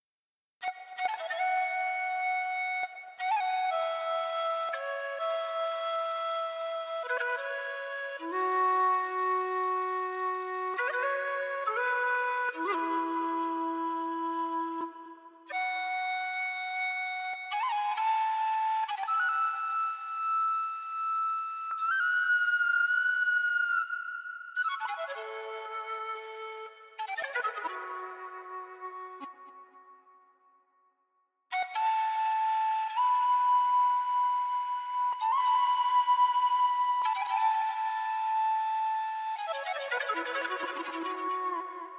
Πάρτε και ένα μονταζο φλάουτο εκεί για καληνύχτα να έχετε Έχω λιώσει. flute.mp3 Ωραίο, ωραίο.